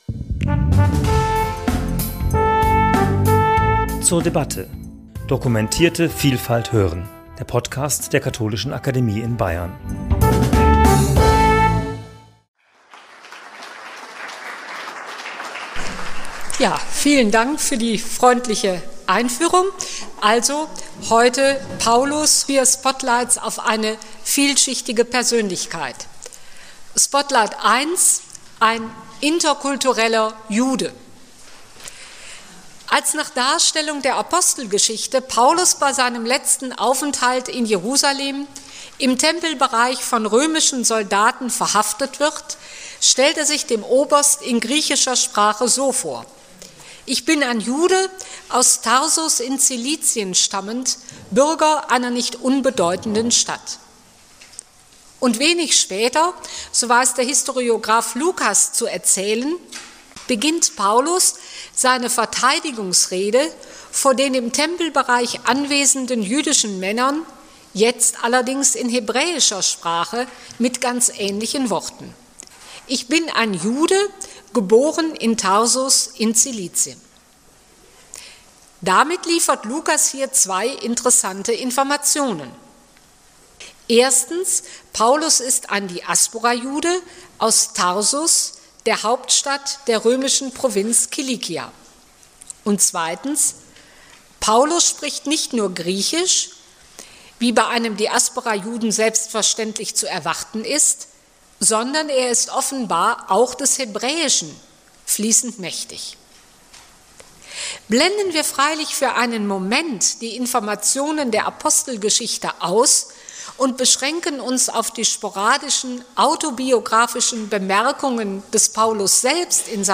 Bei den Biblischen Tagen, 21. bis 23. März 2016, zum ersten Korintherbrief referierte